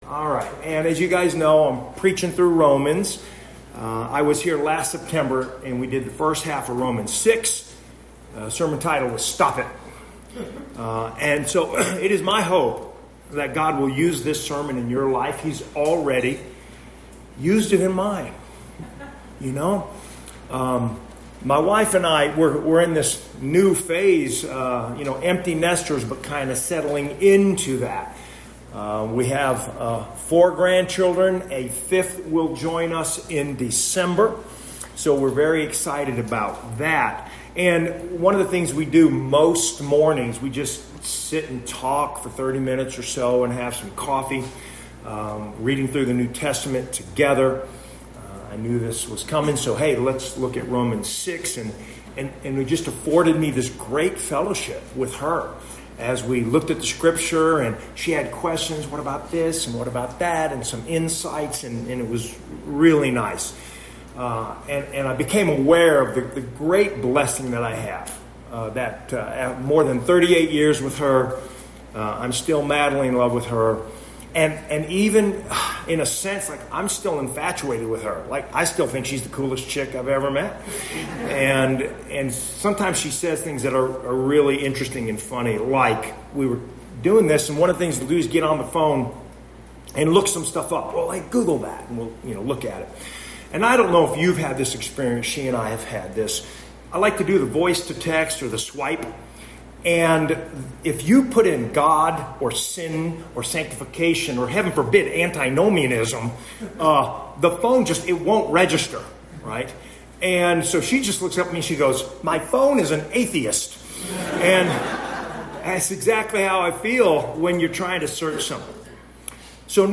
Series: Guest Preacher
Romans 6:12-23 Service Type: Morning Service Stop sinning!